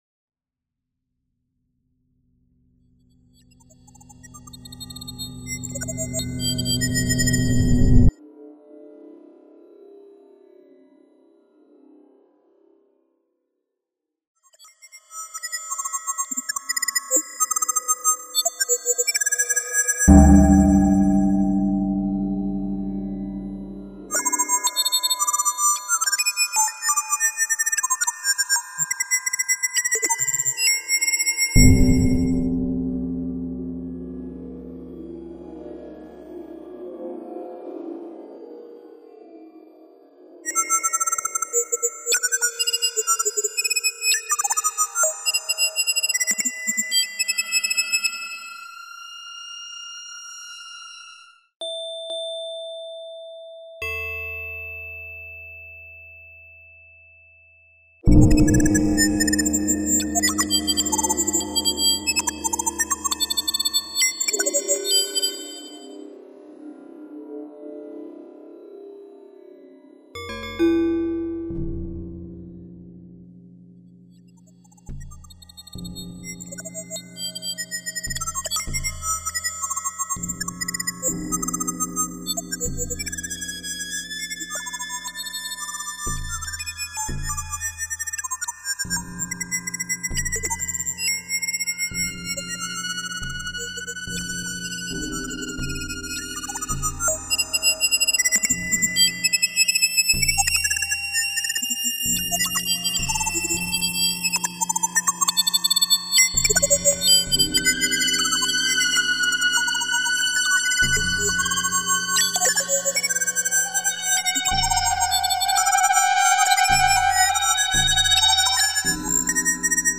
IV CONCURSO INTERNACIONAL DE MINIATURAS ELECTROACÚSTICAS